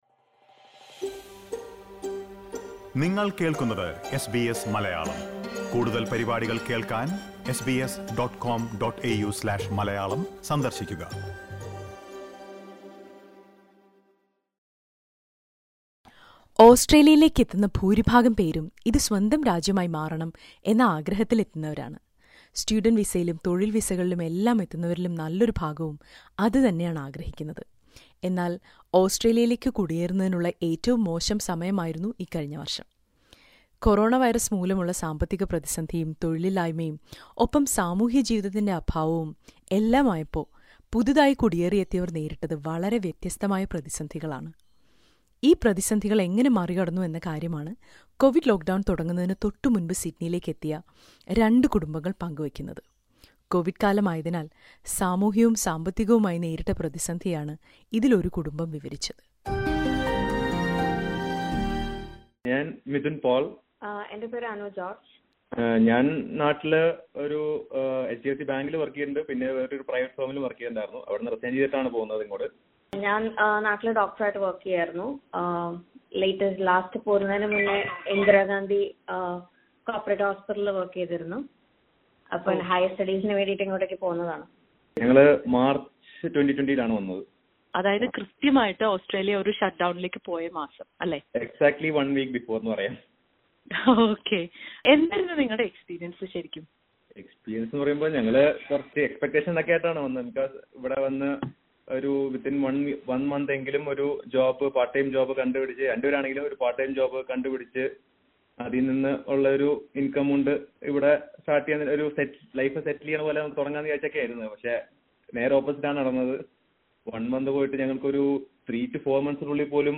Listen to two families which have migrated to Australia just before the coronavirus restrictions were imposed.